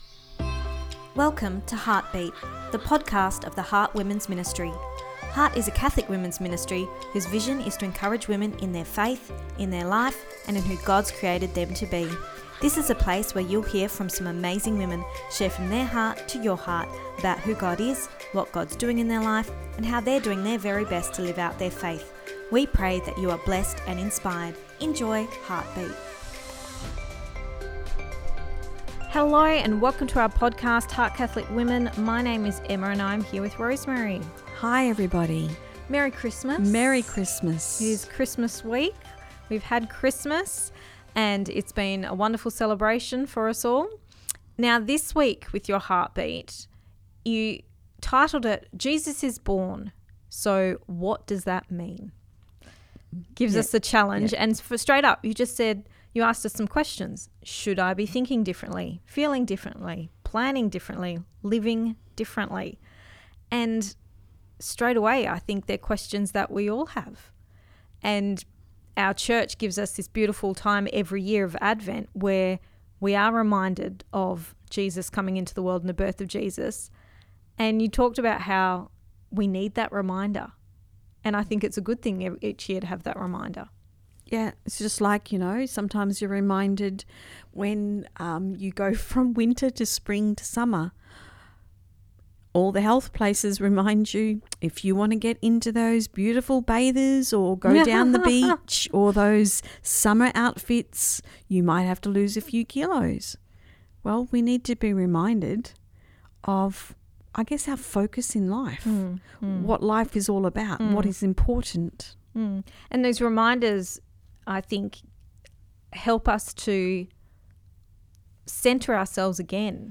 Episode 127 – Jesus is Born. So what does that mean? (Part 2 Our Chat)